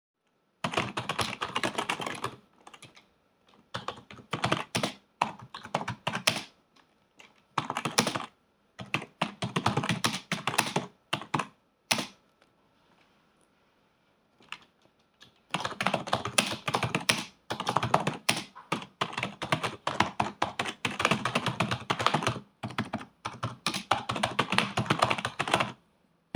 Jest to również typowa klawiatura membranowa – może oczywistość w tej cenie, lecz zdecydowanie warto to nadmienić.
Co jednak warto wspomnieć, klawiatura Aldi jest całkiem głośna. Nie tak głośna, jak HyperX Alloy Origins 65, lecz dalej generuje spore ilości hałasu.
Posłuchajcie tylko nagrania, które ilustruje dźwięki generowane przez użytkowanie klawiatury.
recenzja_aldi_klawiatura_test.mp3